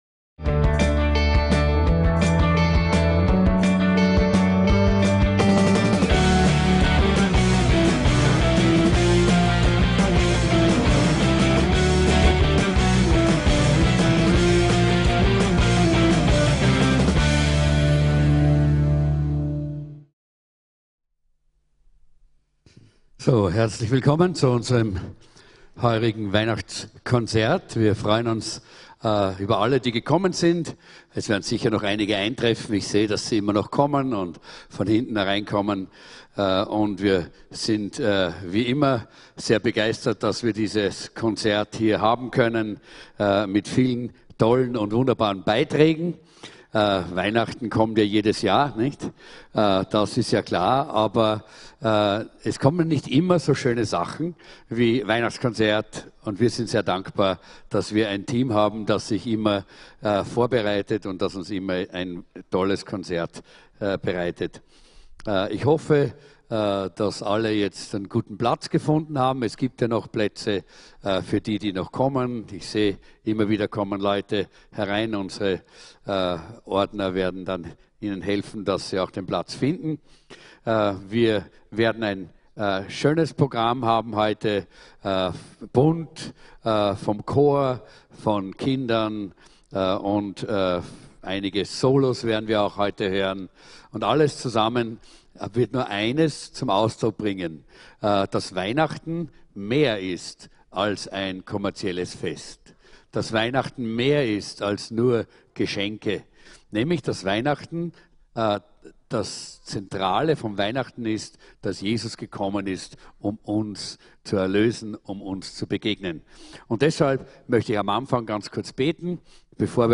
WEIHNACHTSKONZERT ~ VCC JesusZentrum Gottesdienste (audio) Podcast
Weihnachtskonzert des VCC Jesuszentrum mit modernen Gospel Weihnachtsliedern sowie traditionellen Weihnachtsliedern.